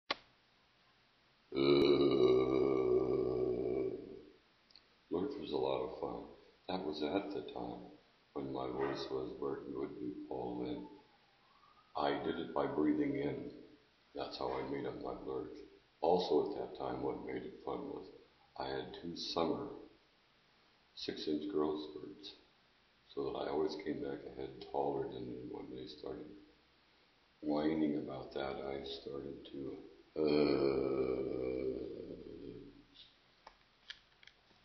Once when I pulled a breath in real fast it made a real low sound.
Loose enough for the vocal cord to slap each other.